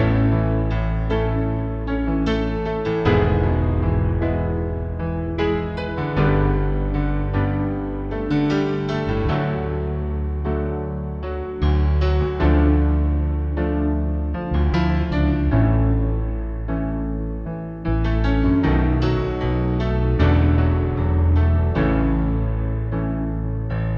One Semitone Down Pop (2010s) 3:31 Buy £1.50